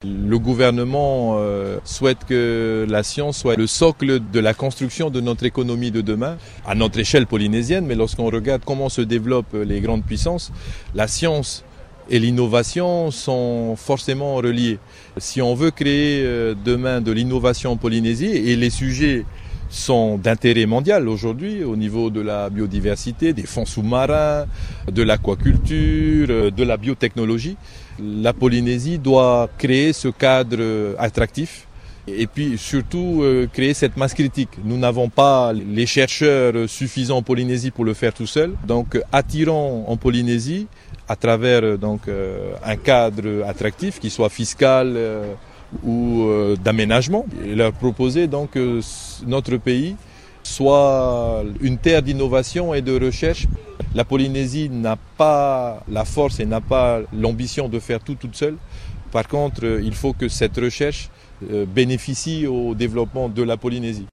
A l’occasion des 50 ans de l’IRD, l’Institut de Recherche et de Développement, ce jeudi, Tearii Alpha, ministre des ressources marines, a déclaré vouloir que la Polynésie devienne un « hub scientifique », comprenez une plaque tournante de la recherche.
Écoutez les précisions de Tearii Alpha :